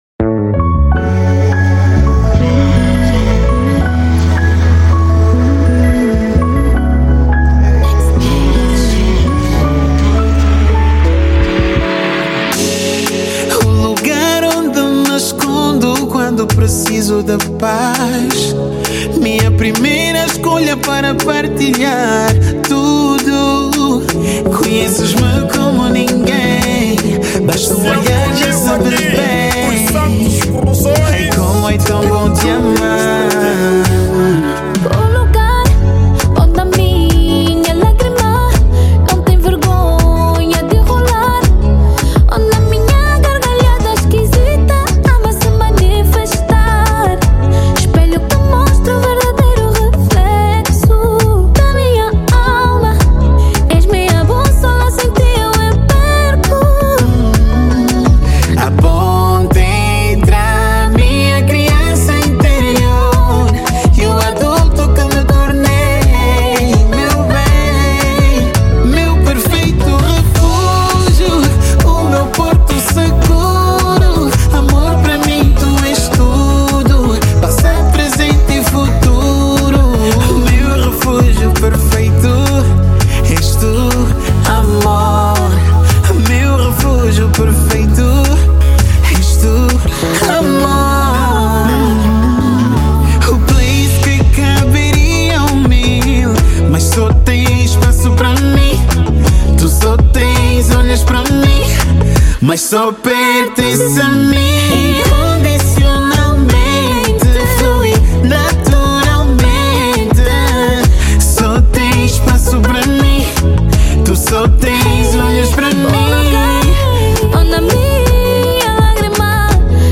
ESTILO DA MÚSICA:  Zouk
MUSIC STYLE: Kizomba/Zouk